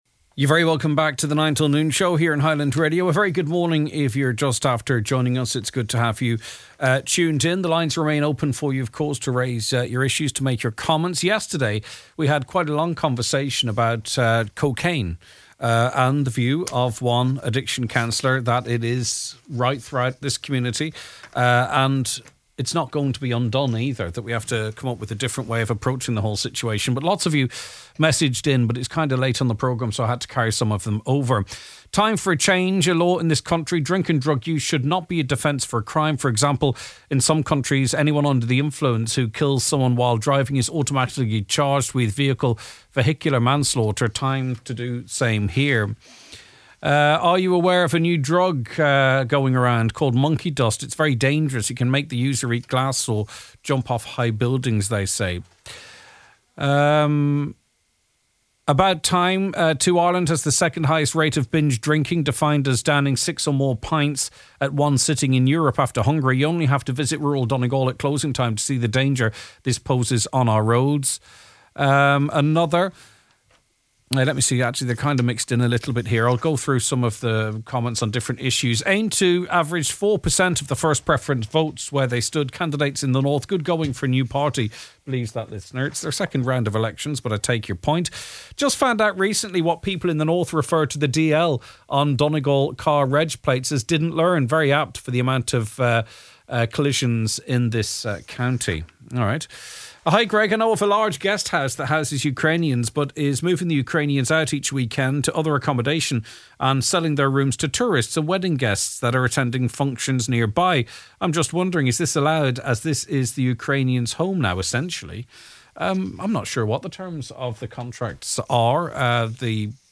The Nine Til Noon Show is broadcast live weekdays 9am til 12noon!
Gerry Adams is our guest in hour two and later we have advice for parents of a child who is being bullied in school: